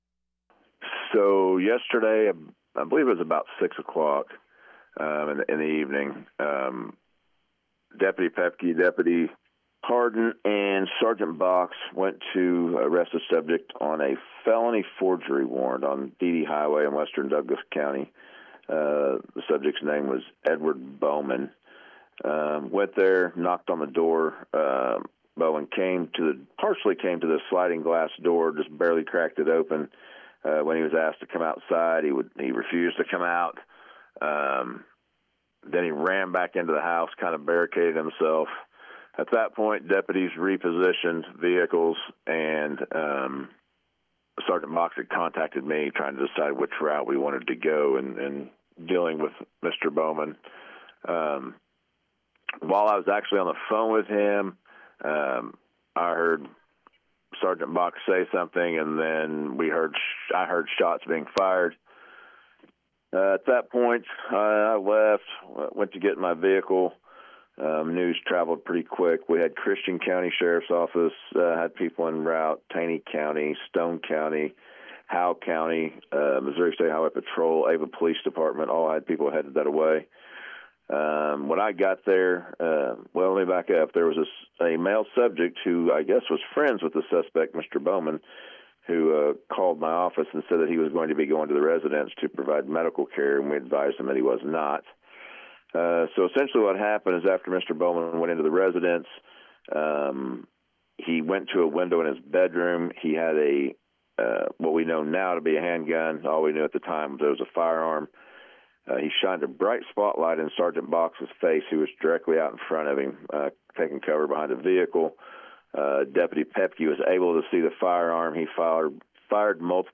Sheriff Chris Degase gave us audio, recalling the events as they took place: